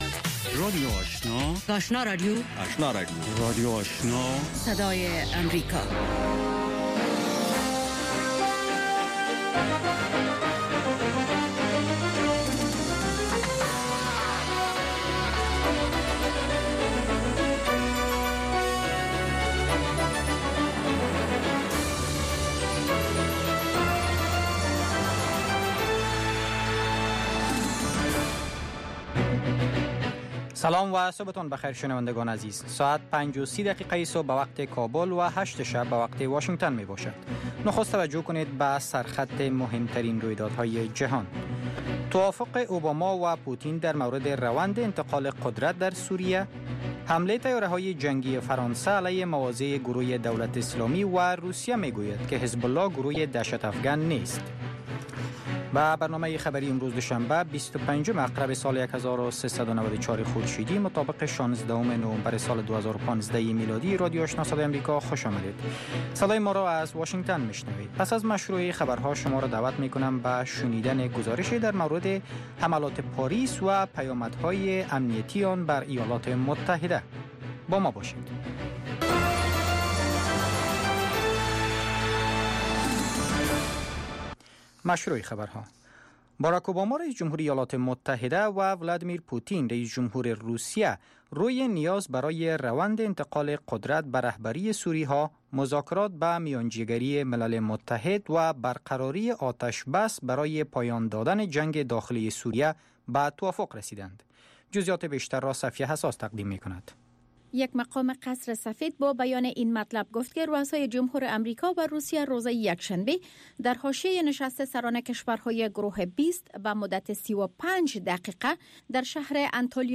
اولین برنامه خبری صبح